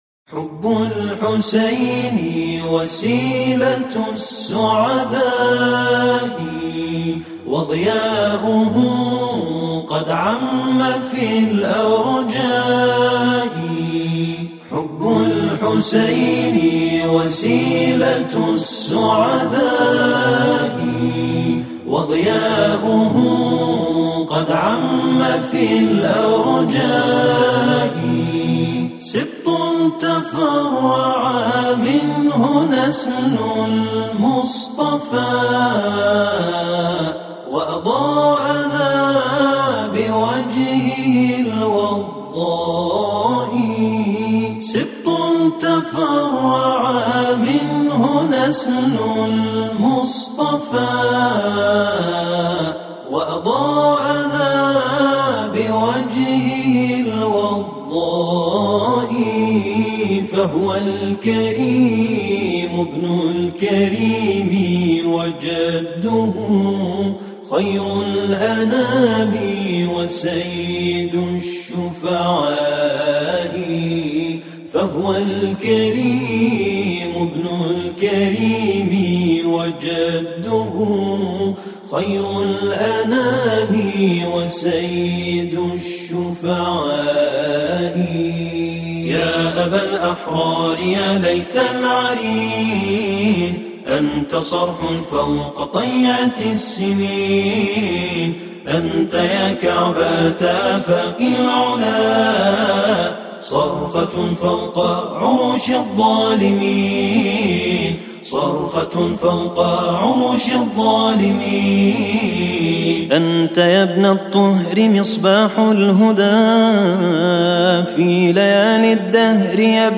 حُبُ الحسين (ع) - ولادة سيد الشهداء (ع) - مدائح